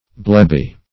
Blebby \Bleb"by\, a.